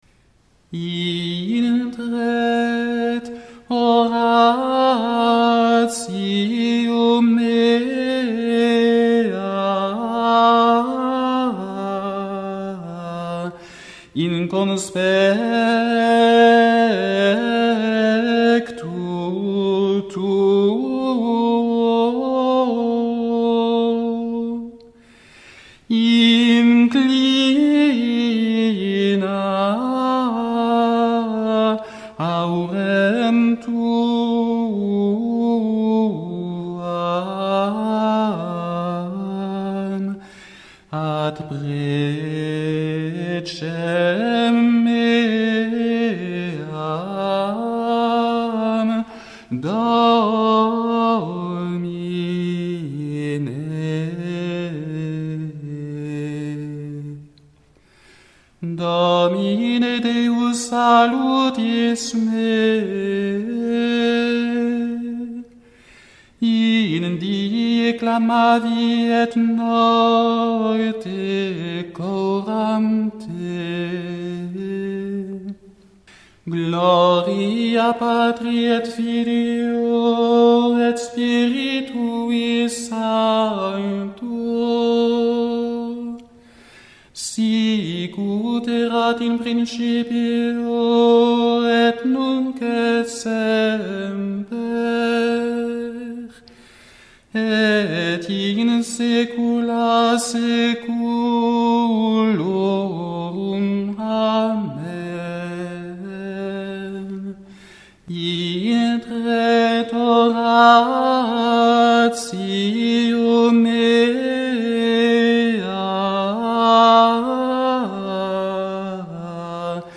L’introït de cette messe, paradoxalement, est bref. Et il est en troisième mode, le mode « mystique », contemplatif, donc a priori intime. Pourtant son insistance sur la dominante omniprésente, ce do très haut par rapport à la tonique, et le rythme de la mélodie, évoquant une imposante procession, lui donnent bien une allure solennelle, tout en renforçant son côté contemplatif dans les hauteurs mystiques.
Le voici donc correctement chanté, mais par un soliste (trouvé sur cette page de L'Homme nouveau, avec un long et intéressant commentaire).